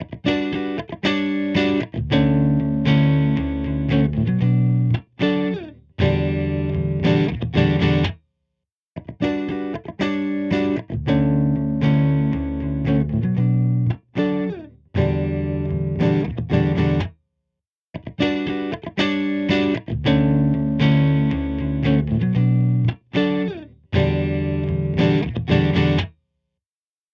EQ45 | Electric Guitar | Preset: Crispy Guitar
EQ45-Crispy-Guitar.mp3